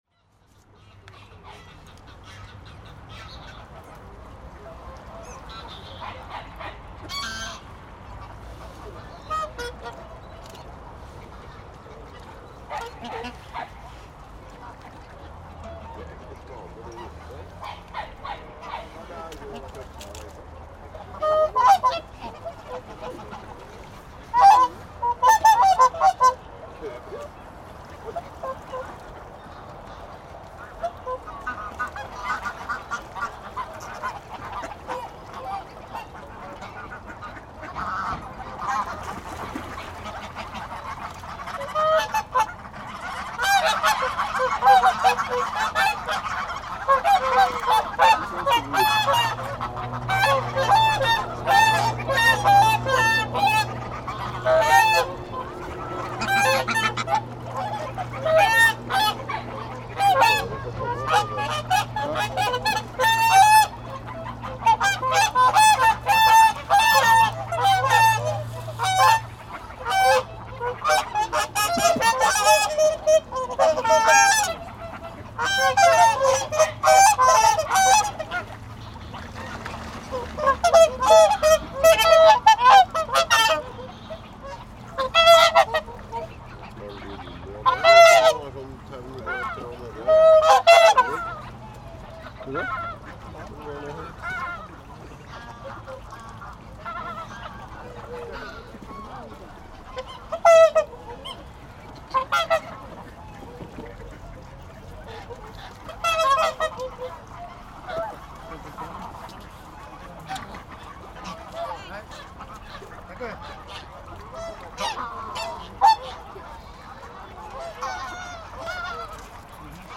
Þarna má heyra hundgá, í fólki gefa öndum brauð og í útlendum ferðamönnum.
In Reykjavik center is a quiet big pond or a lake with many bird species, like Swans, Gooses and Ducks. Often people feed this birds with bread so outburst is normal when birds grasp the breadcrumbs.
On the steel bridge above was two cardioid in XY setup.
Above the pond.